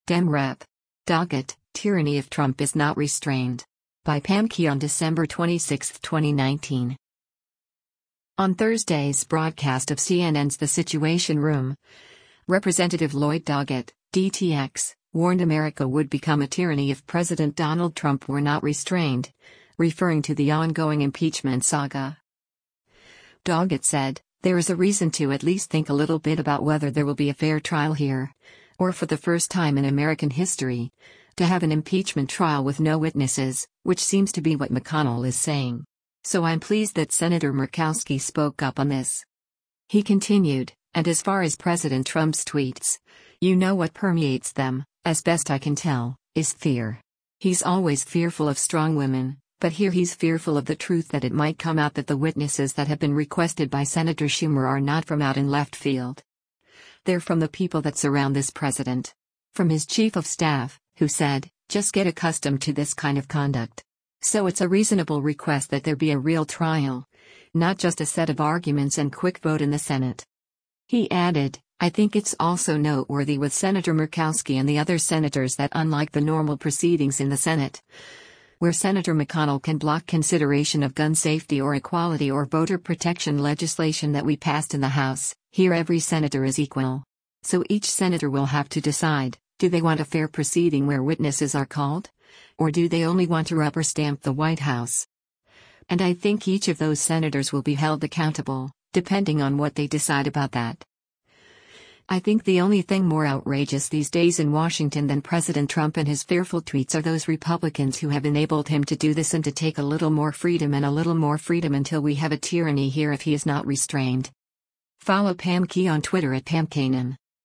On Thursday’s broadcast of CNN’s “The Situation Room,” Rep. Lloyd Doggett (D-TX) warned America would become a “tyranny” if President Donald Trump were not “restrained,” referring to the ongoing impeachment saga.